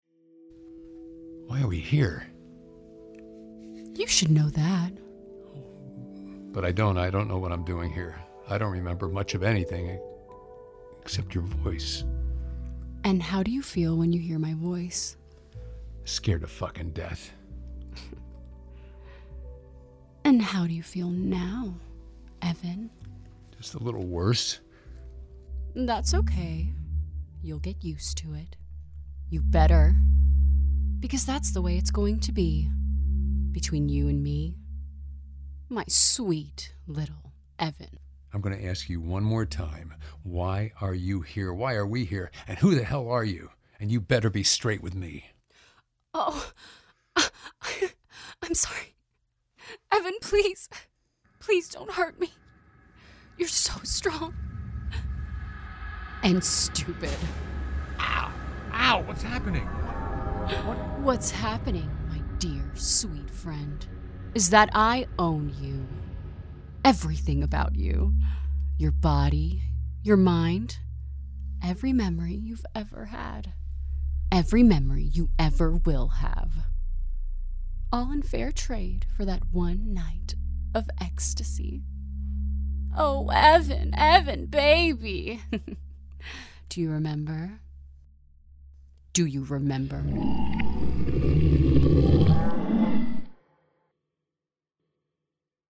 Female Voice Over, Dan Wachs Talent Agency.
Vivacious, Enthusiastic, Bilingual
Drama